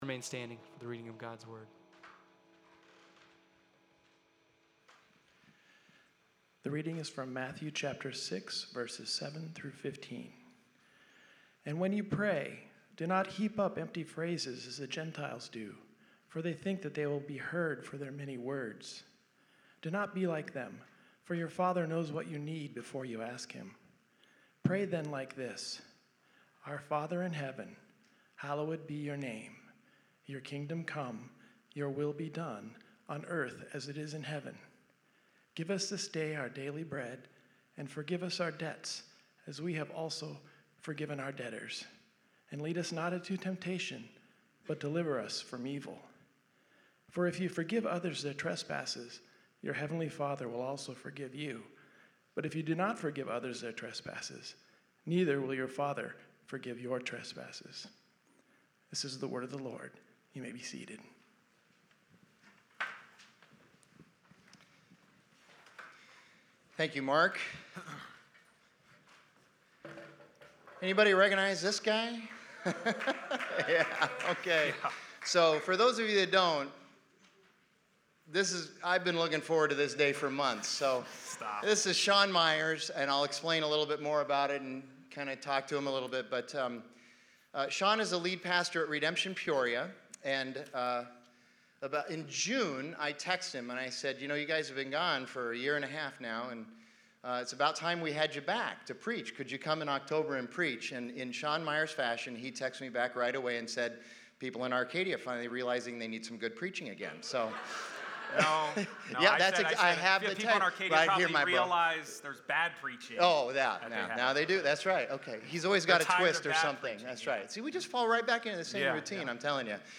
Sermon on the Mount: The Lord's Prayer from Redemption Arcadia Sermons.